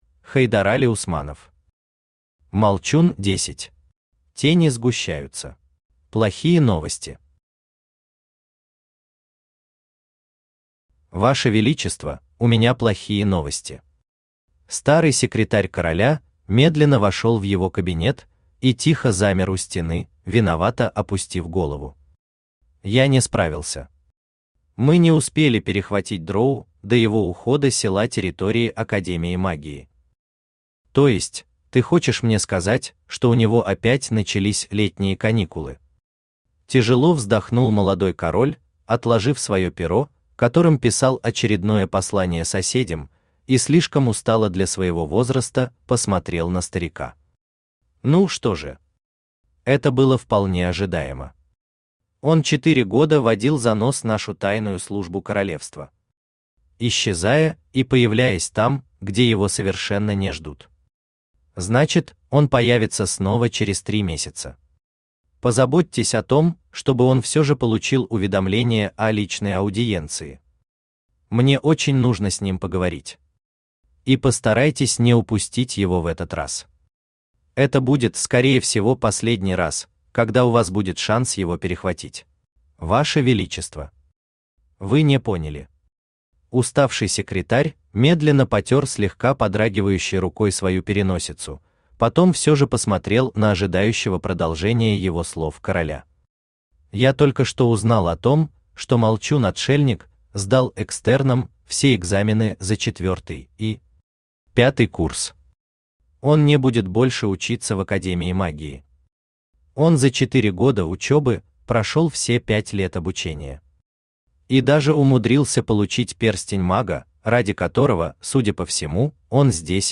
Аудиокнига Молчун 10. Тени сгущаются | Библиотека аудиокниг
Тени сгущаются Автор Хайдарали Усманов Читает аудиокнигу Авточтец ЛитРес.